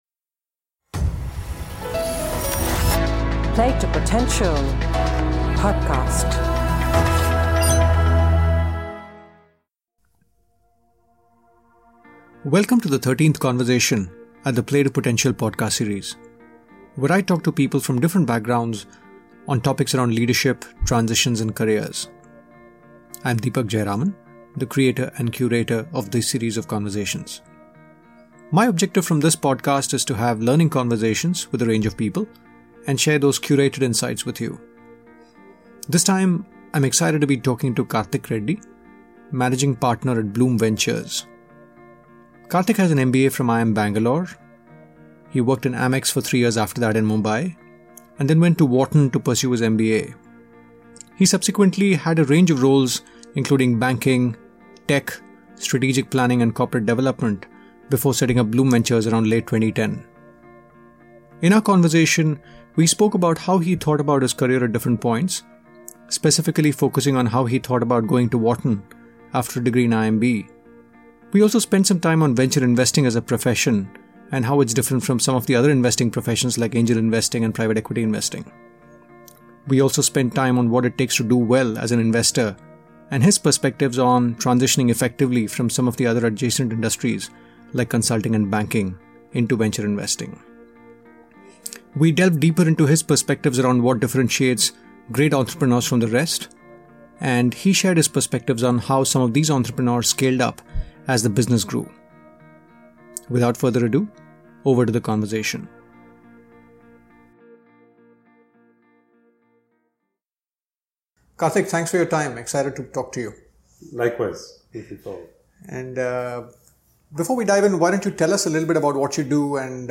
ABOUT THE PODCAST Play to Potential podcast started in Dec 2016 and features conversations around three broad themes - Leadership, Transitions and Careers.